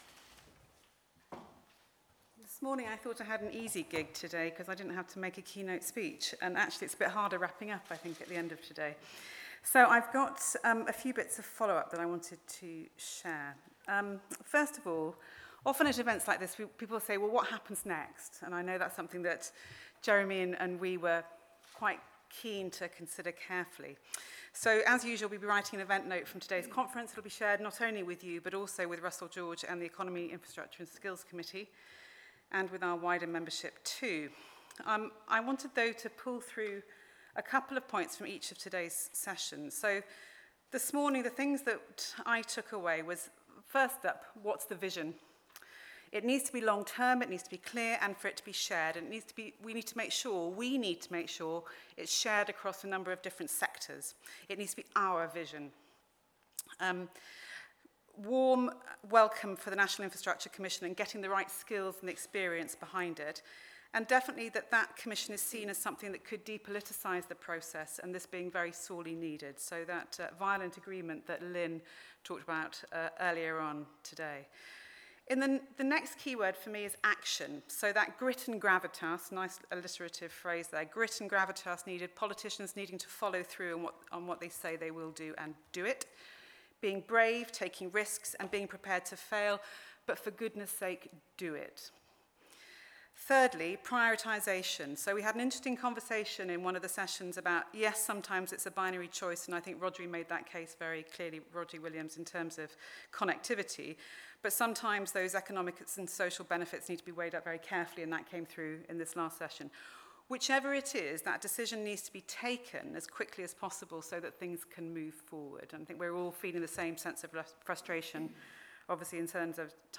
September 2017: Closing comments
28 September 2017, Radisson Blu hotel.